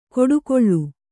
♪ koḍukoḷḷu